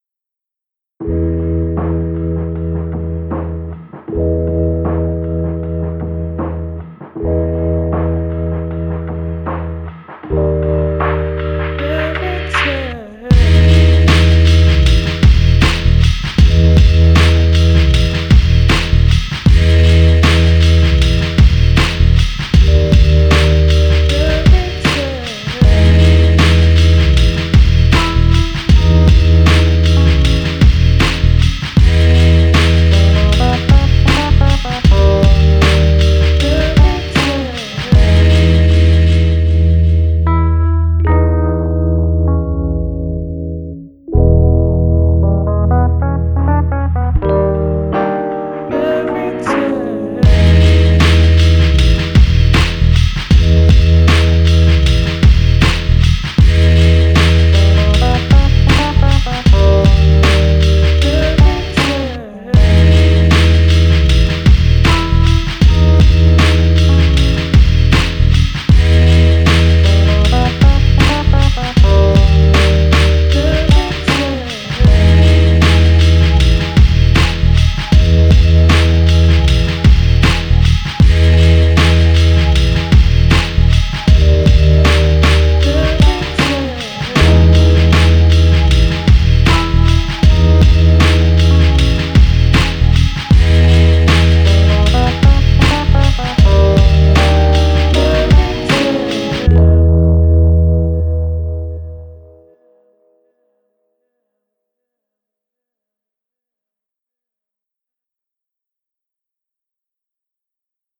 Laidback organic downbeat with warm jazzy rhodes and